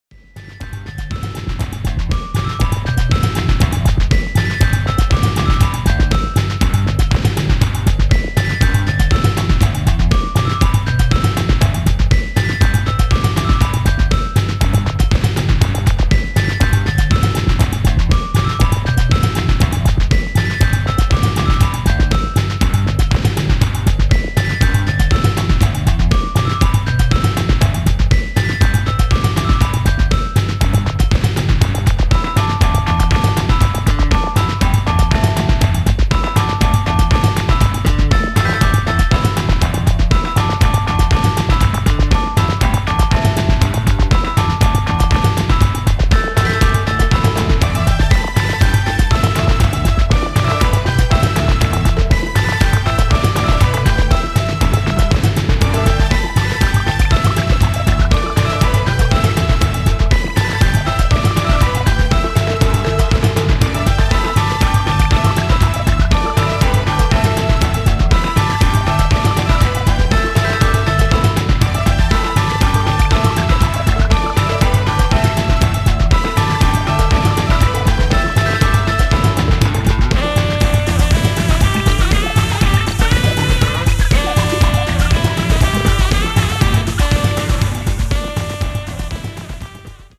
アジアンなネタ選びとフリーキーなサウンドメイクで展開するB-1